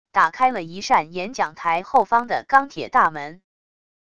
打开了一扇演讲台后方的钢铁大门wav音频